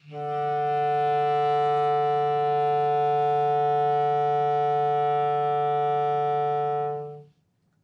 DCClar_susLong_D2_v3_rr1_sum.wav